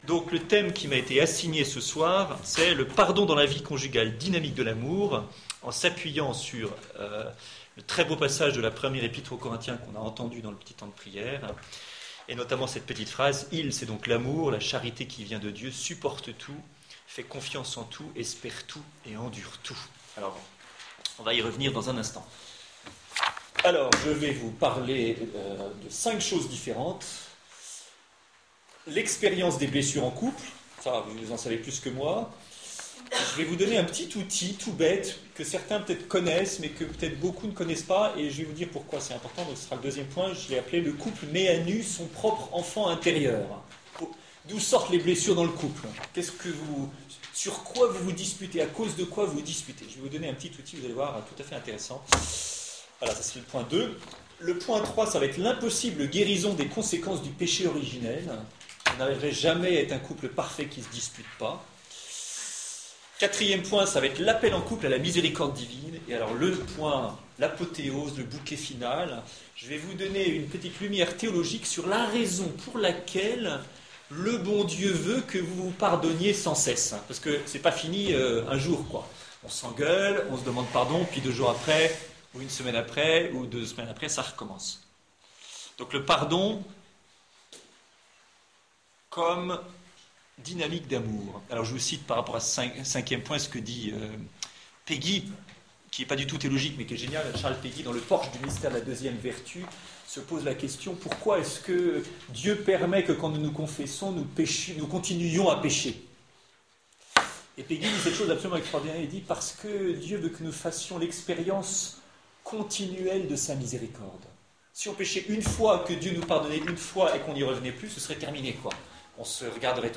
Conférence 03/12/14 : Le pardon dans la vie conjugale, dynamique de l’Amour